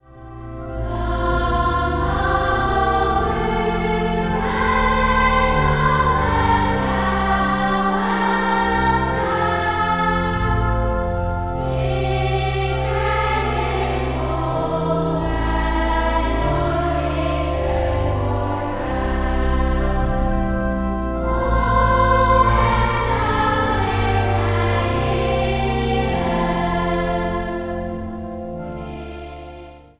orgel
piano
fluit
viool
contrabas
Zang | Kinderkoor